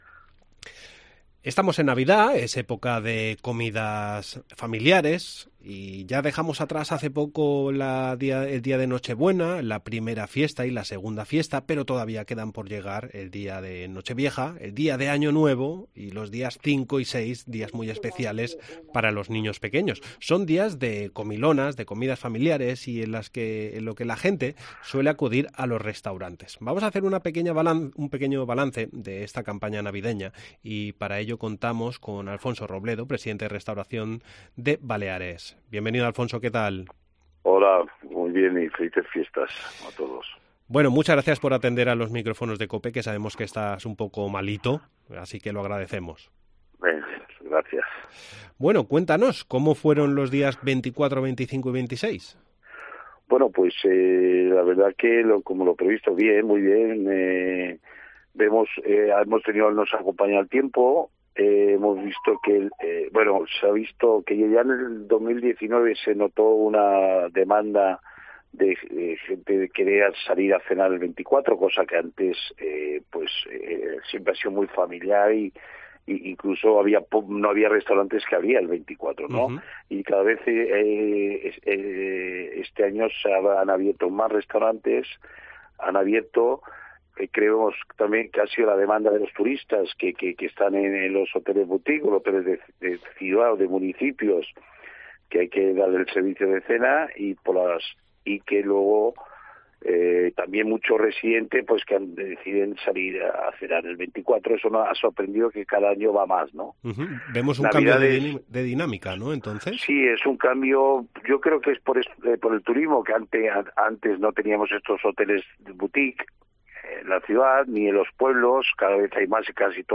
atiende a los micrófonos de COPE Baleares para hacer balance del año